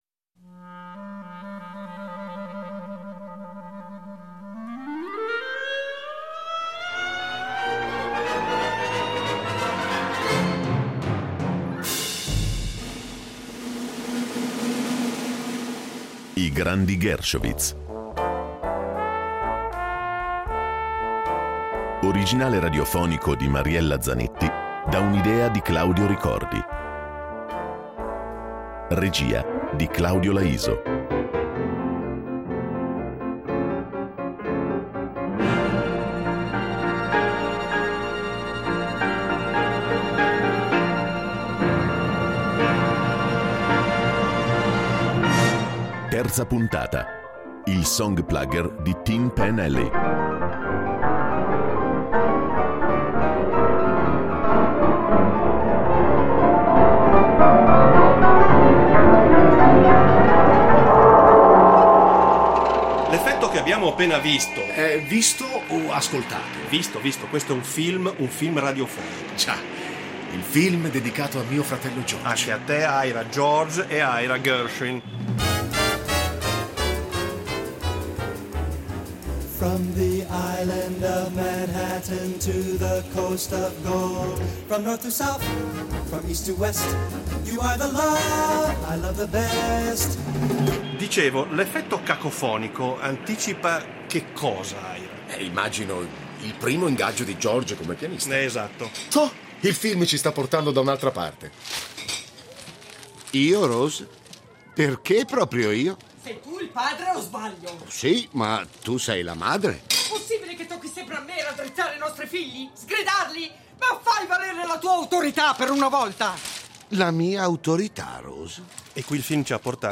radiodramma dedicato allo straordinario rapporto tra Ira e George Gershowitz